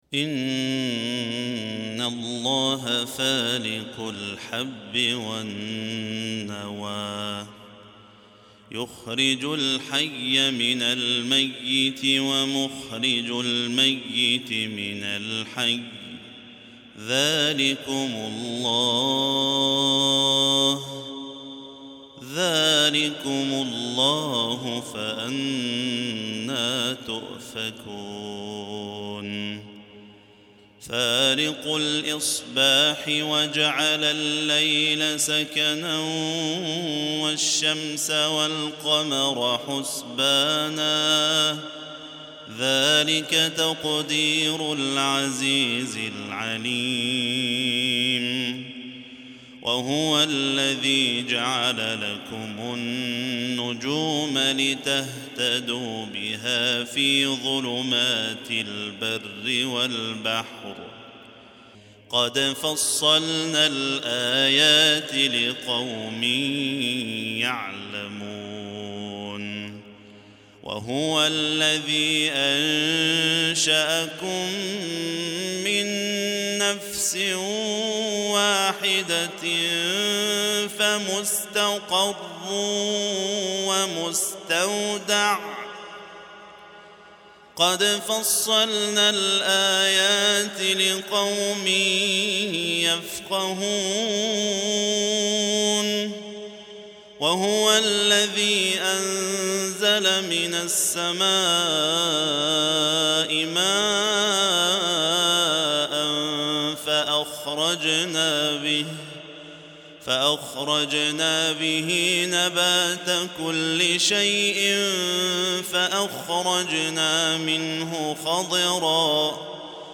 عشائية بديعة